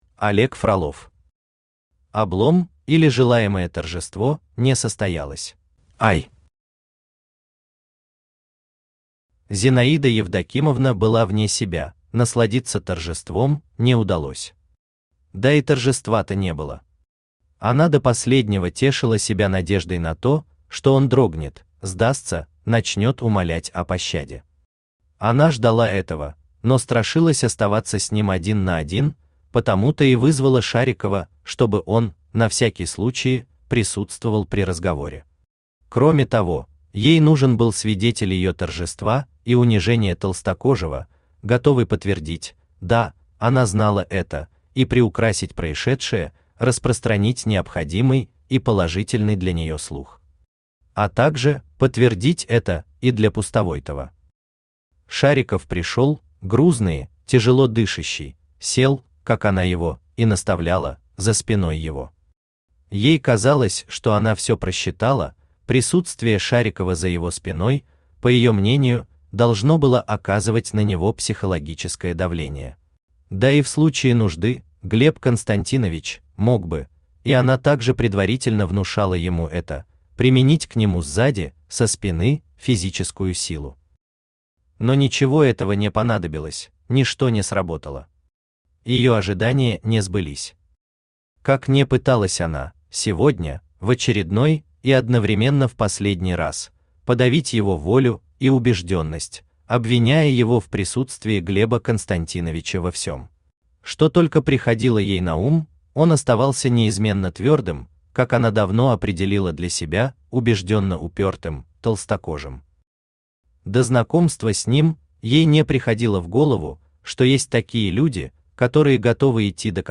Аудиокнига Облом, или Желаемое торжество не состоялось | Библиотека аудиокниг
Aудиокнига Облом, или Желаемое торжество не состоялось Автор Олег Васильевич Фролов Читает аудиокнигу Авточтец ЛитРес.